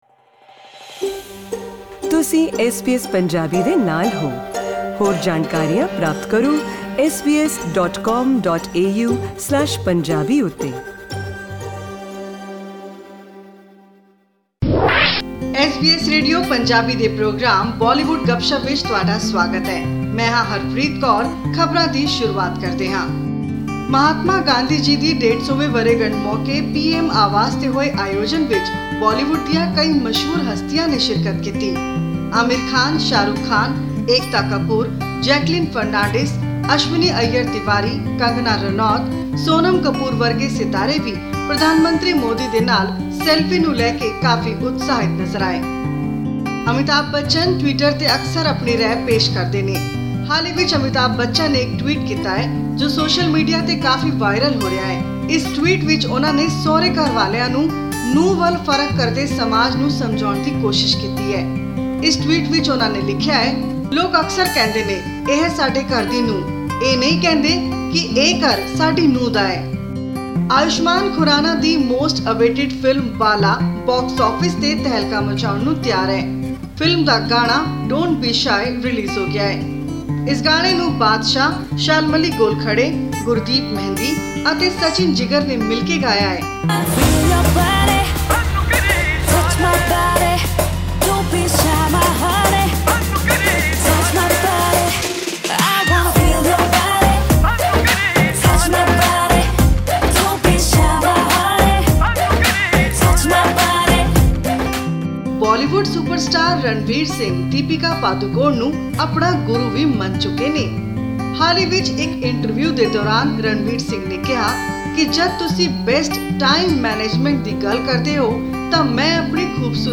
Enjoy some news stories and clips of up-coming songs in this week's Bollywood Gupshup.